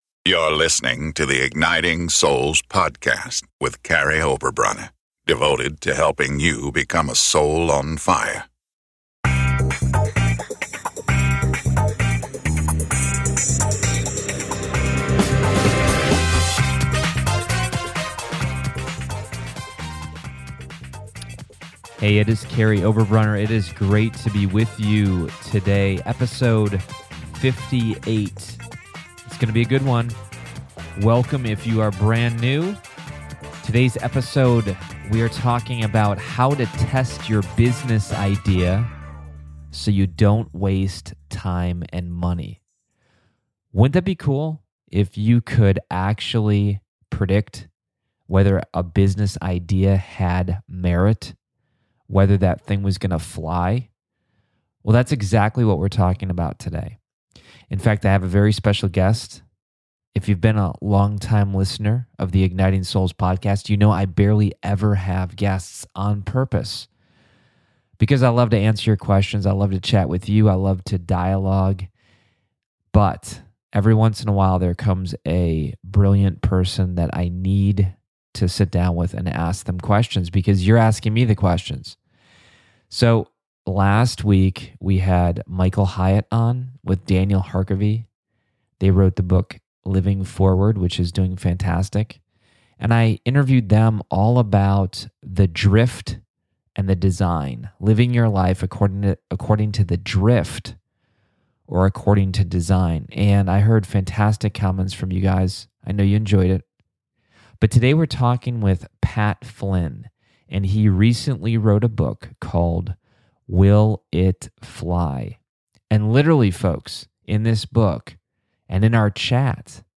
In this candid conversation, I sit down with serial entrepreneur Pat Flynn and unpack the finer points of WILL IT FLY—a manual for how to test your next business idea so you don't waste your time and money.